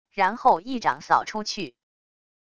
然后一掌扫出去wav音频生成系统WAV Audio Player